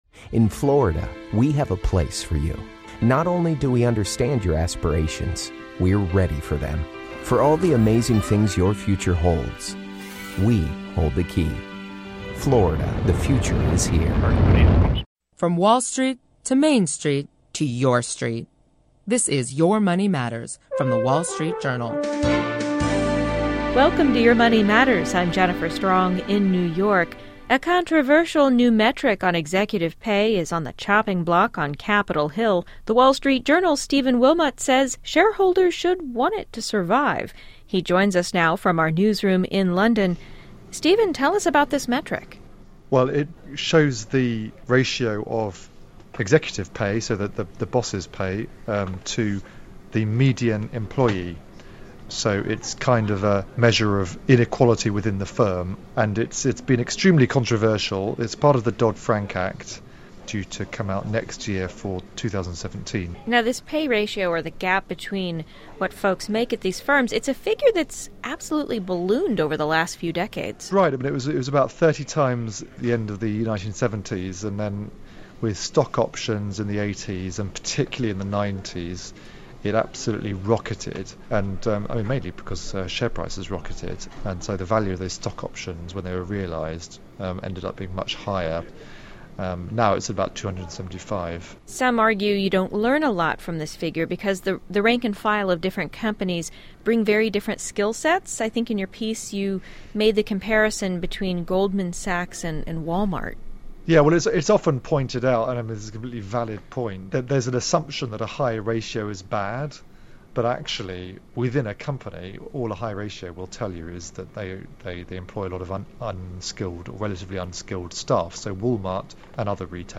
reports from London on why investors should care.